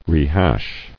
[re·hash]